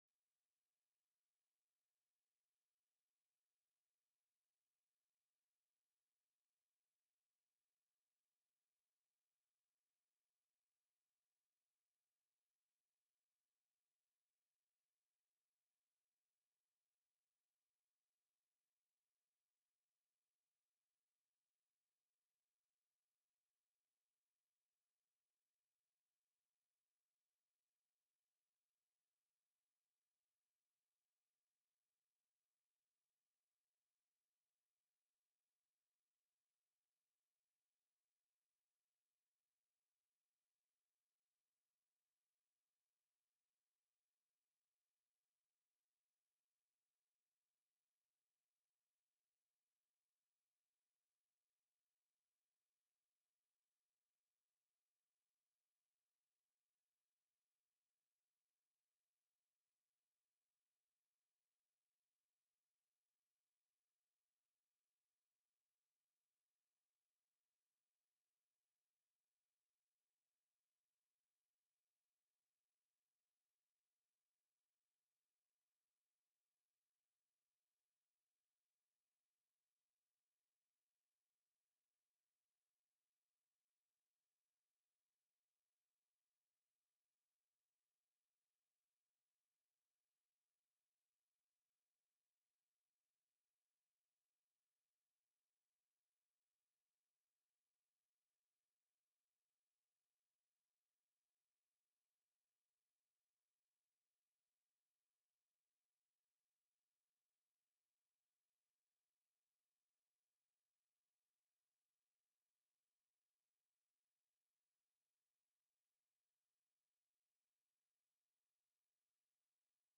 The audio recordings are captured by our records offices as the official record of the meeting and will have more accurate timestamps.
1:42:44 PM REPRESENTATIVE ZACH FIELDS, SPONSOR, introduced the bill.